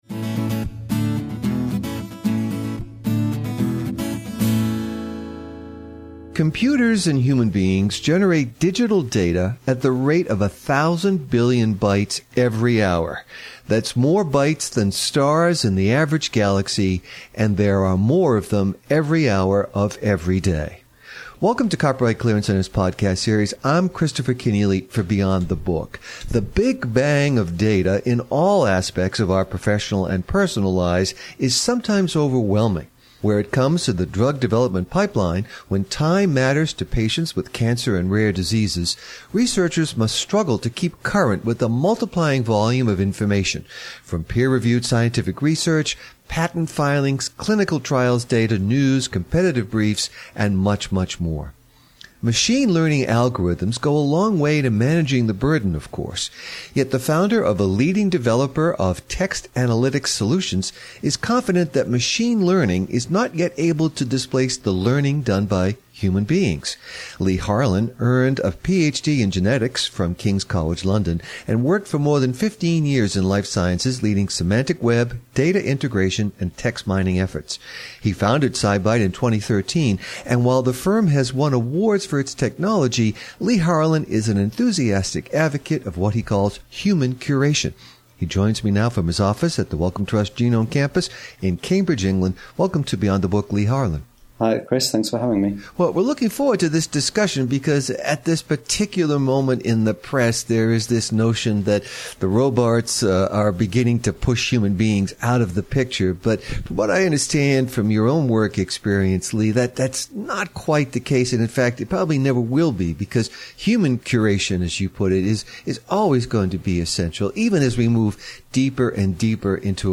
What are Ontologies – And How Are They Built? An Interview